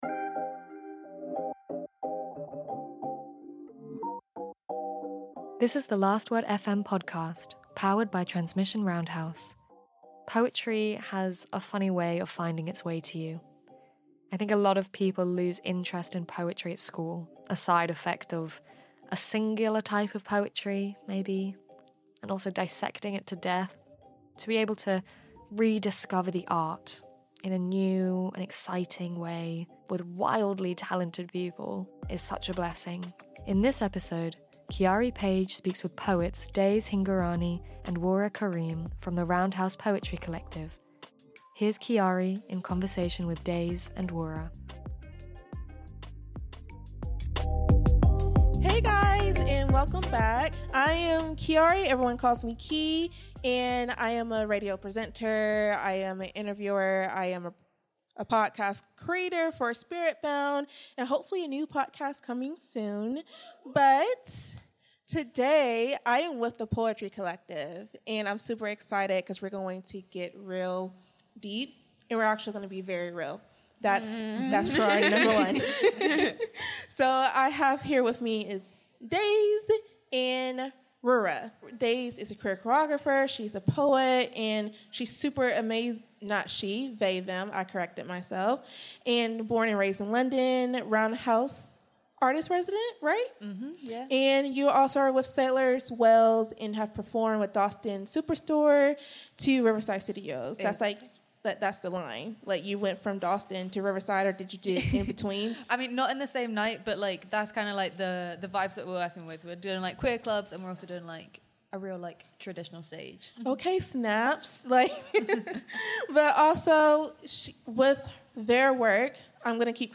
They discuss being interdisciplinary artists, working with difficult emotions, the experience of being in a collective and the act of calling yourself a poet. The Last Word FM is an annual live broadcast powered by Transmission Roundhouse with talks, interviews and panels with a number of incredible artists whose work was featured as part of The Last Word Festival.